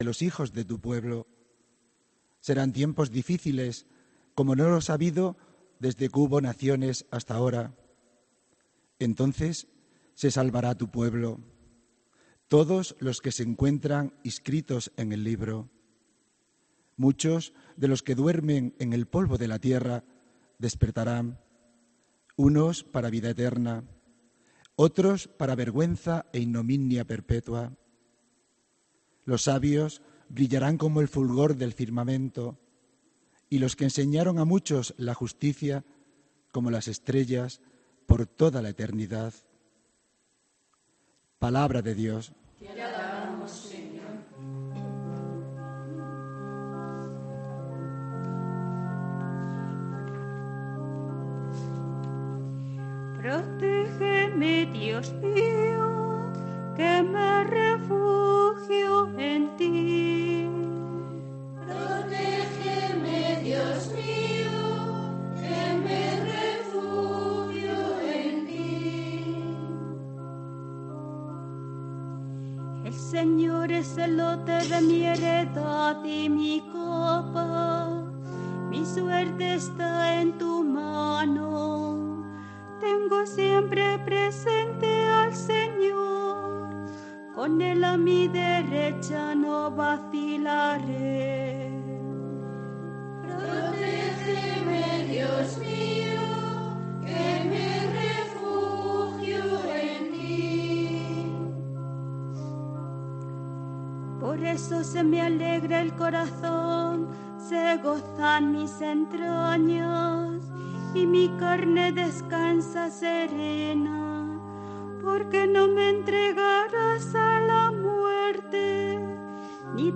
HOMILÍA 18 DE NOVIEMBRE DE 2018